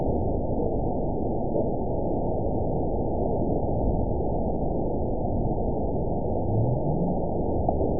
event 922845 date 04/19/25 time 02:12:41 GMT (1 month, 4 weeks ago) score 9.53 location TSS-AB02 detected by nrw target species NRW annotations +NRW Spectrogram: Frequency (kHz) vs. Time (s) audio not available .wav